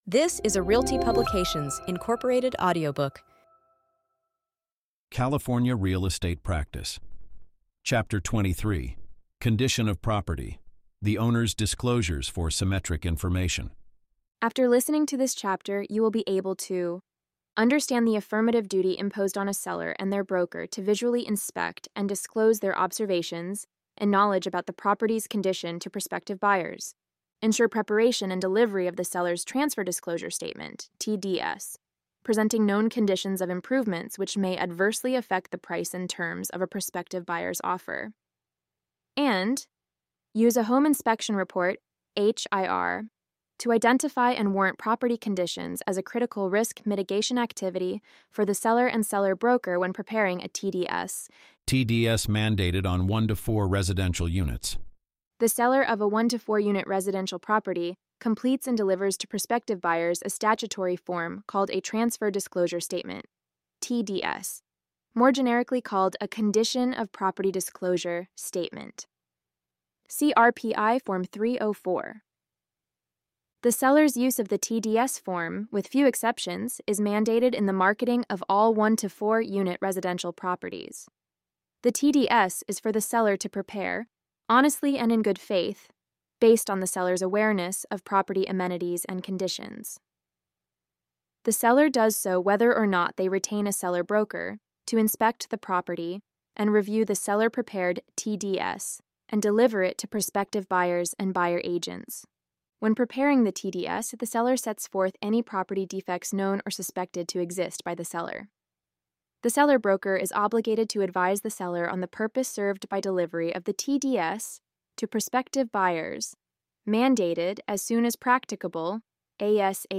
Follow along with an audio reading of this article adapted as a chapter from our upcoming Real Estate Practice course update.